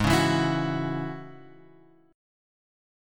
G# 7th Suspended 4th Sharp 5th